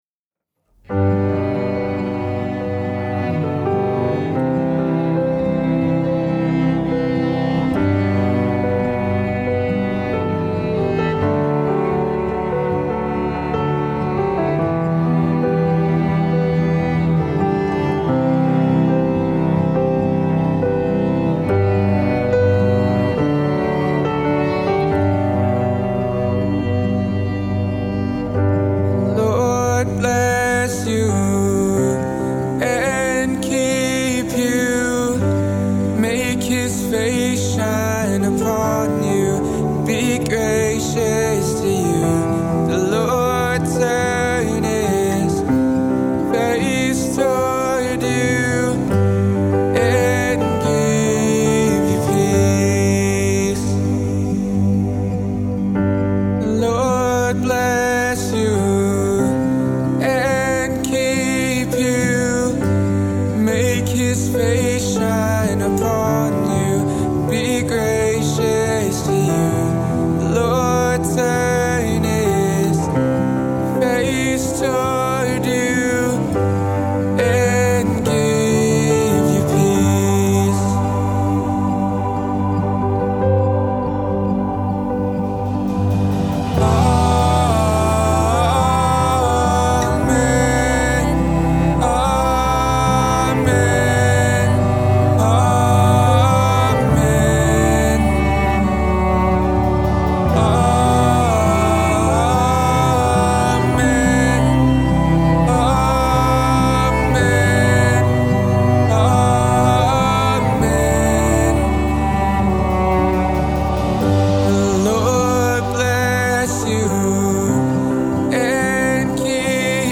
vocals, violin, keys
drums, vocals
electric guitar, acoustic guitar, keys, vocals
cello